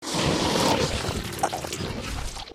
fracture_eat_2.ogg